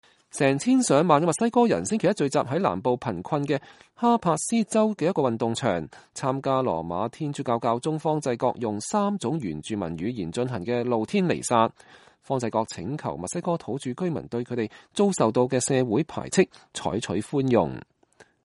教宗在墨西哥受到熱烈歡迎
成千上萬的墨西哥人星期一聚集在南部貧困的恰帕斯州一個運動場，參加羅馬天主教教宗方濟各用三種原住民語言進行的露天彌撒。方濟各請求墨西哥土著居民對他們遭受到的社會排斥採取寬容。